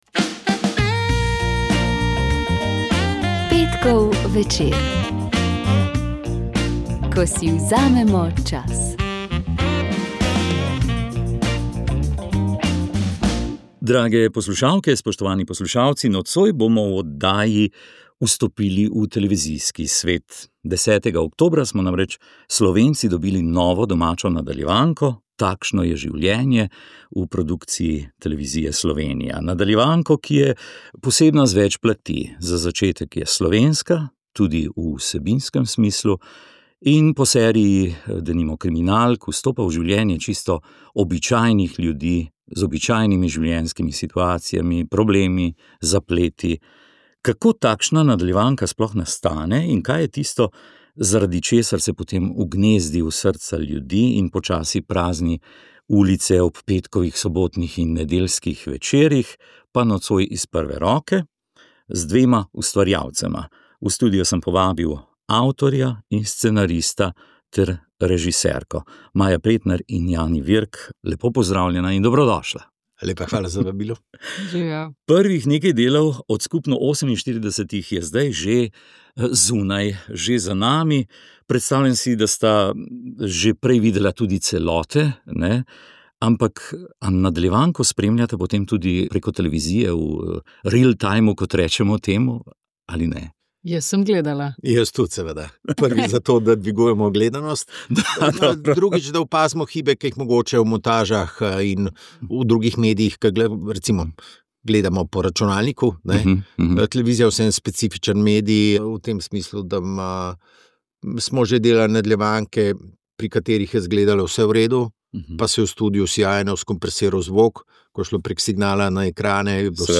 In prav njihova glasba je bila rdeča nit tokratne oddaje Luč v temi. Slišali pa ste tudi poslanico ob mednarodnem dnevu psov vodičev, prispevek učencev iz centra Iris ter poročilo o izobraževanju s področja spletne trgovine.